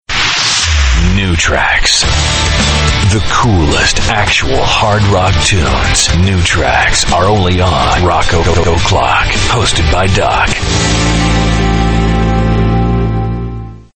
RADIO IMAGING / ROCK ALTERNATIVE /